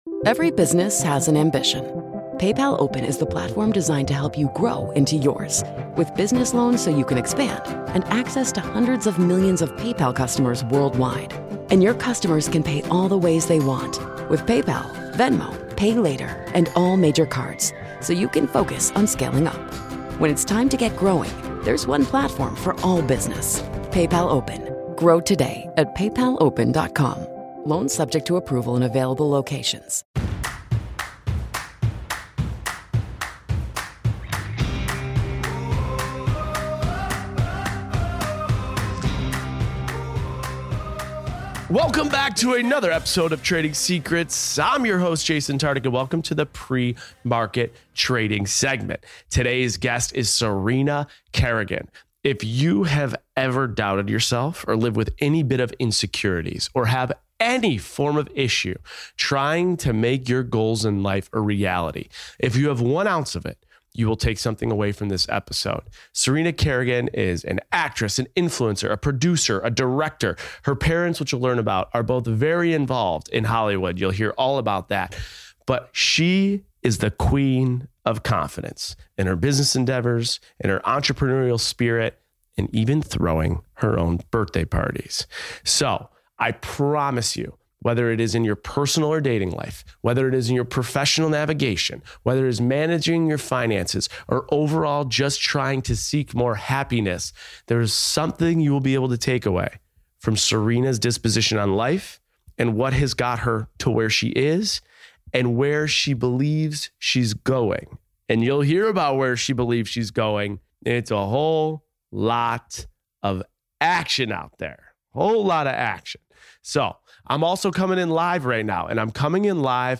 Host: Jason Tartick Co-Host